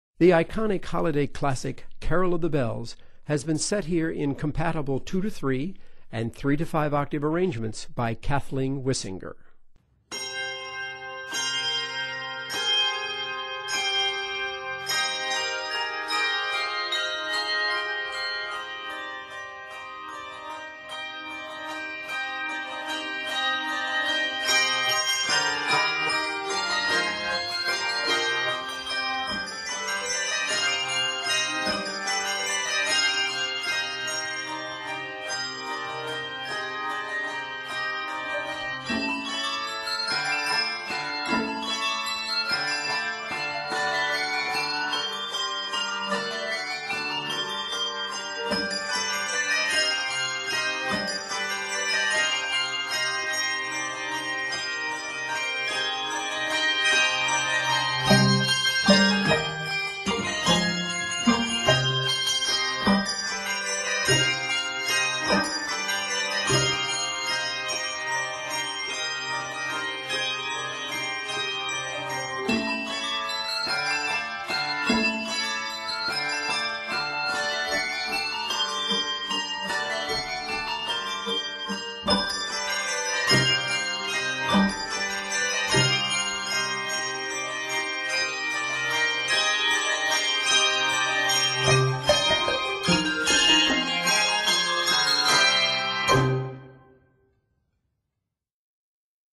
compatible 2-3 and 3-5 octave scores